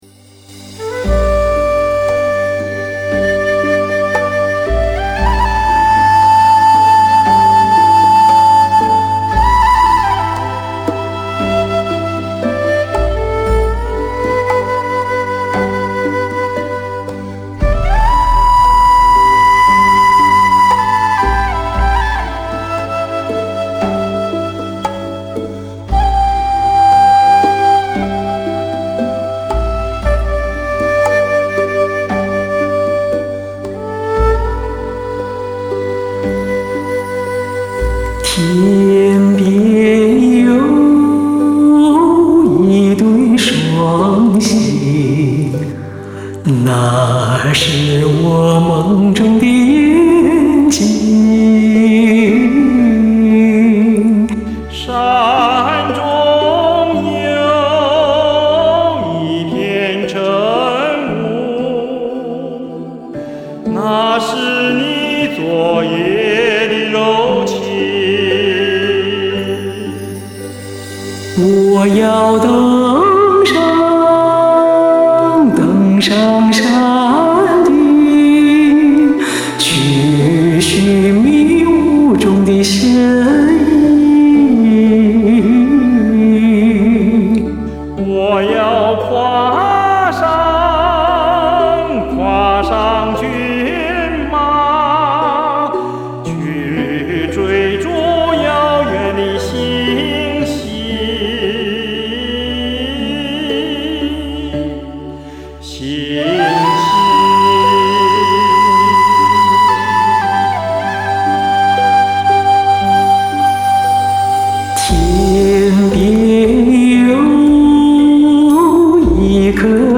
我们这次尝试用女中音和男高音的合唱，再来演绎一个新的版本。女中音和男高音的合唱比较少。